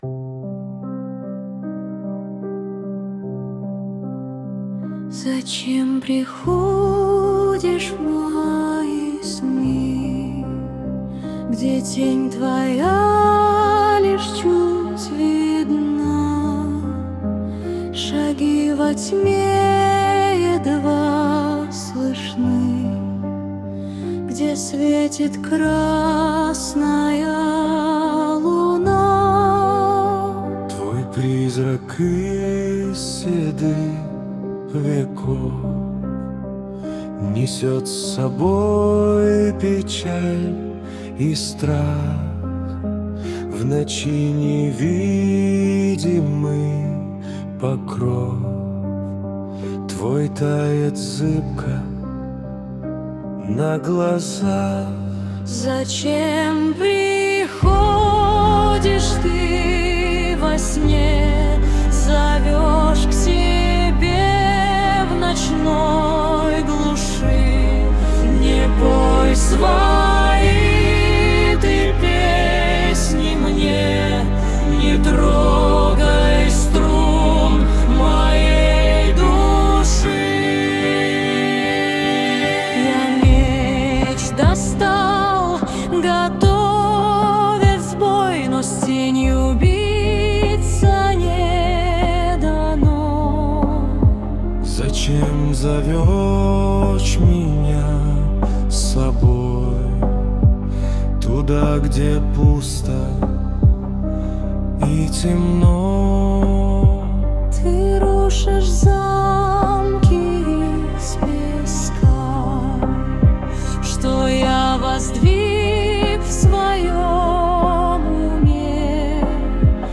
mp3,4374k] AI Generated